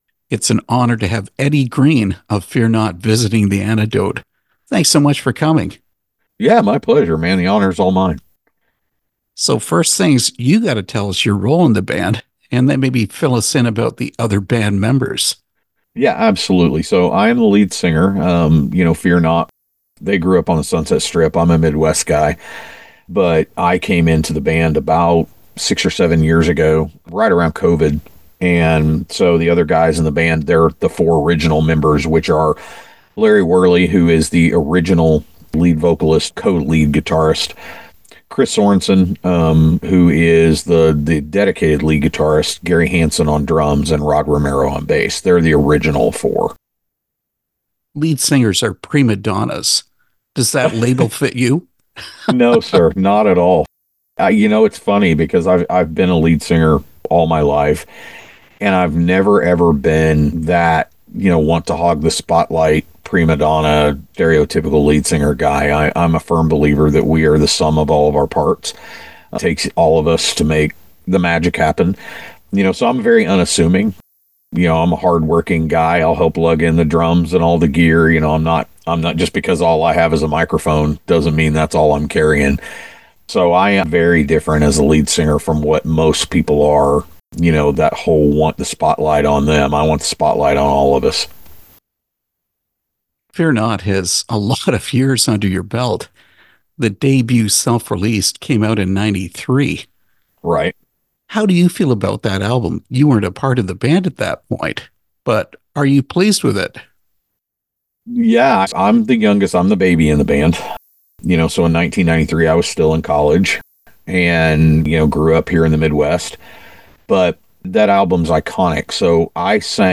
Interview with Fear Not
fear-not-interview.mp3